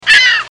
Seagulls 5